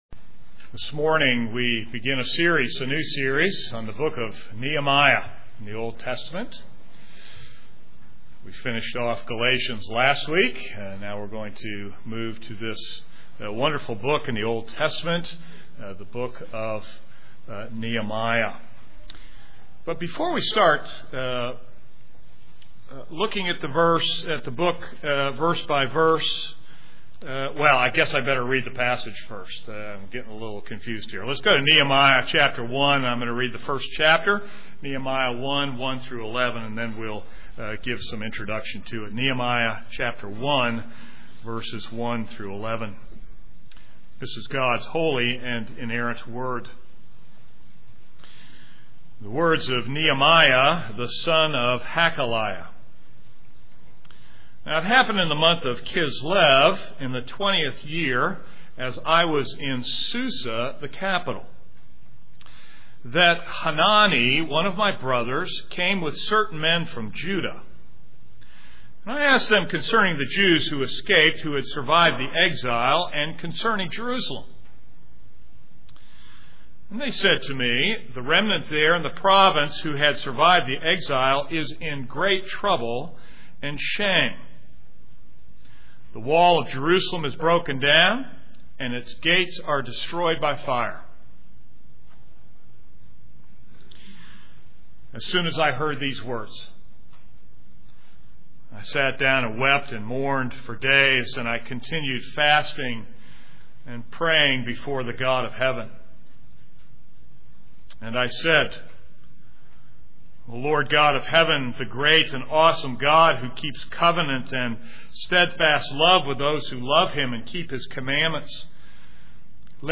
This is a sermon on Nehemiah 1.